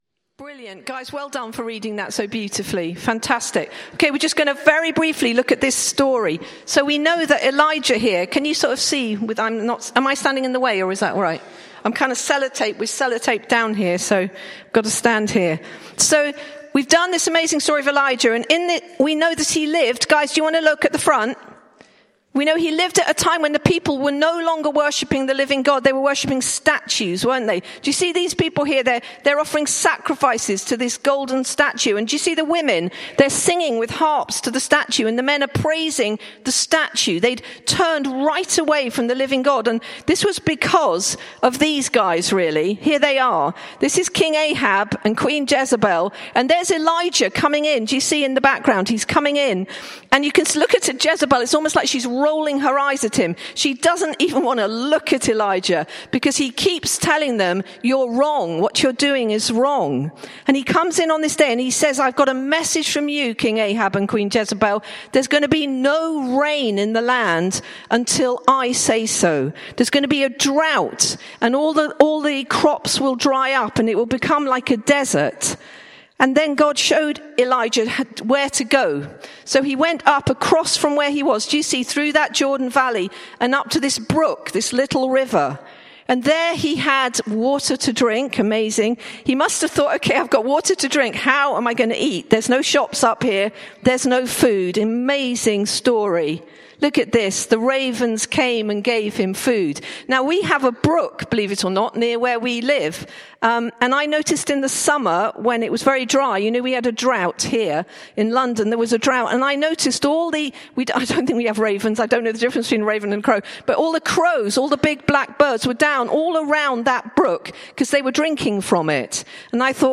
Bassett Street Sermons